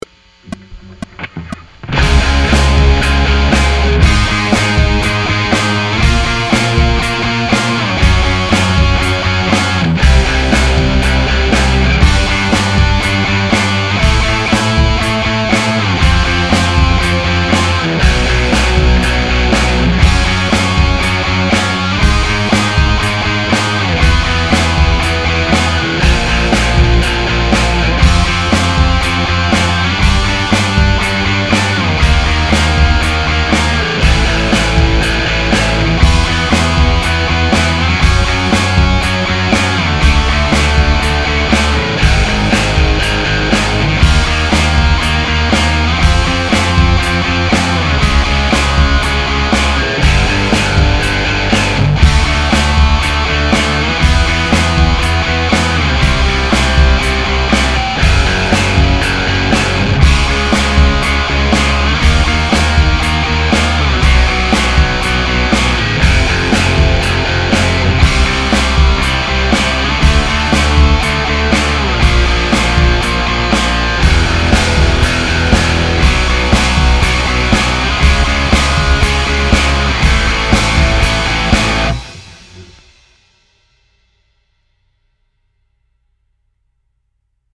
Power Chord Drill II Audio – The Power of Music
Power-Chord-Drill-II-B.mp3